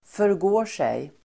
Ladda ner uttalet
förgå sig verb, strike , insult Grammatikkommentar: A & mot B Uttal: [förg'å:r_sej] Böjningar: förgick sig, förgått sig, förgå sig, förgår sig Definition: våldföra sig; förgripa sig (to lose control and attack someone)